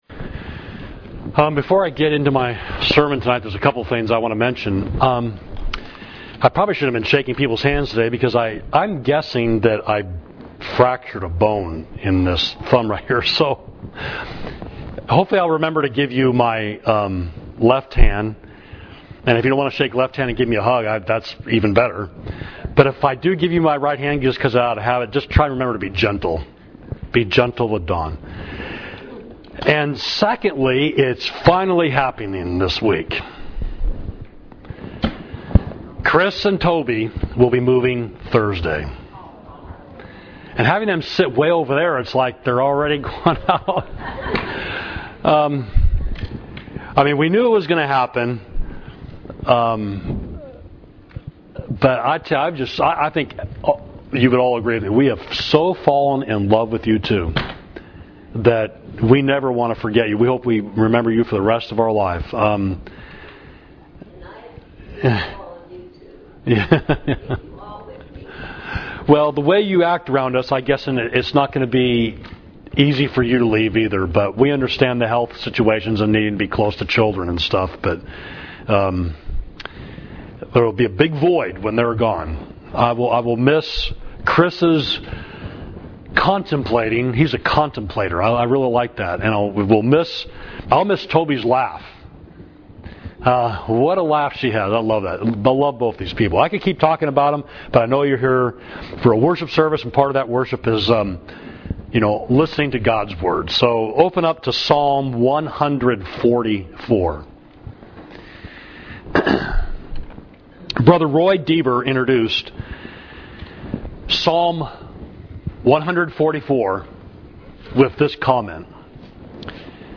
Sermon: Our Heavenly Resource, Psalm 144 – Savage Street Church of Christ